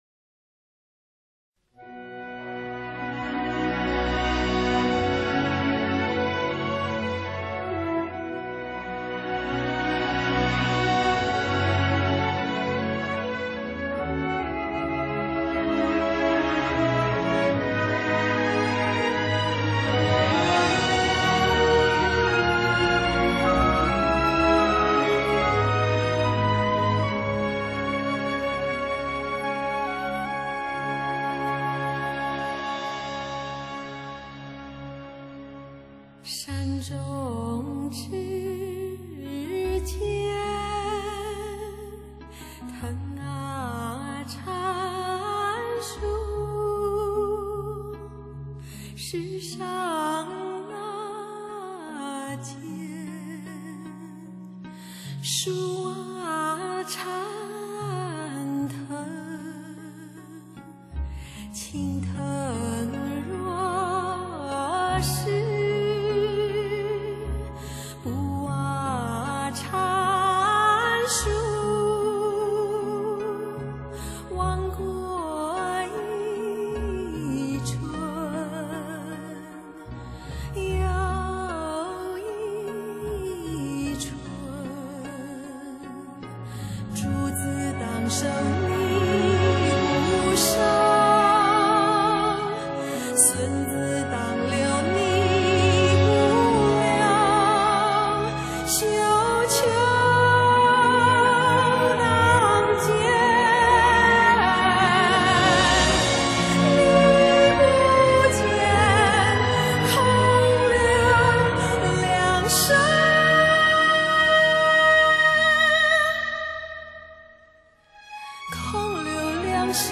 大型山水實景演出中的全部音樂
作品橫跨古典、流行，融會民族、國際的宏大氣魄